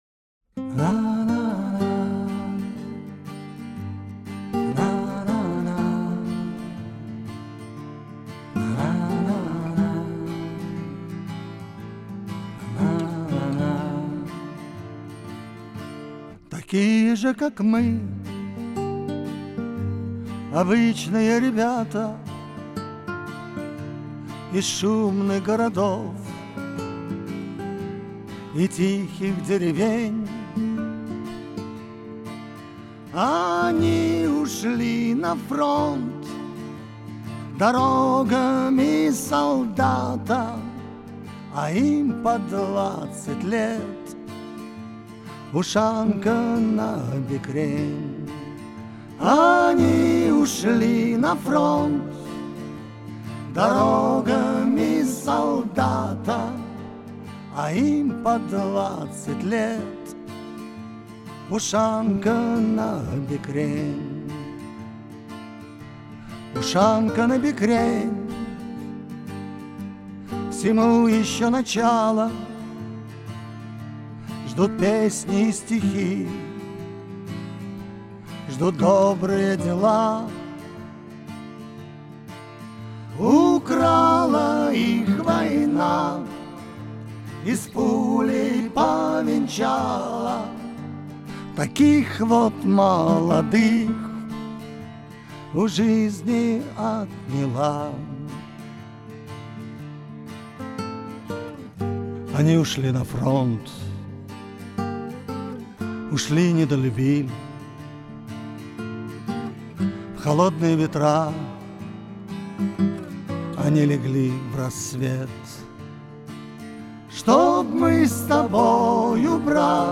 Песня записана на студии